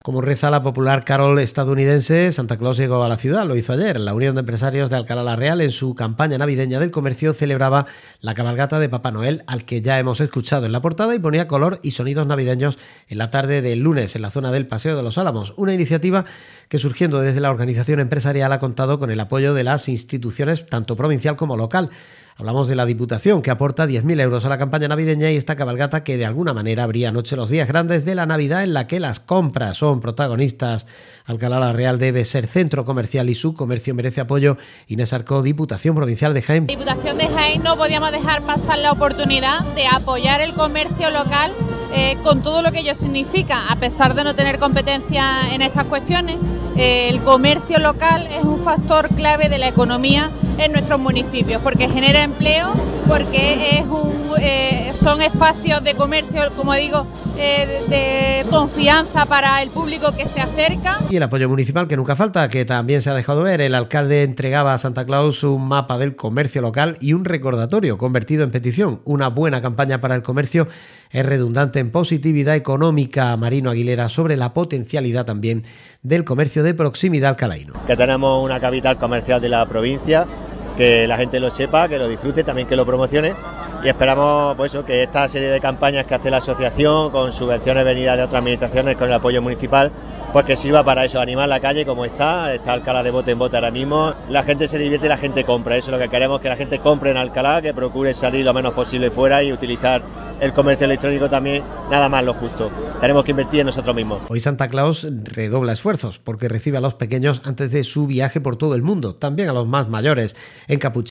Noticia cabalgata en Onda Cero Alcalá Sierra Sur día 24/12/2025 con intervención de Diputación de Jaén en nombre de la Diputada invitada Inés Arco
ondacerocabalgata.mp3